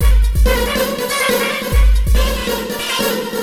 E Kit 09.wav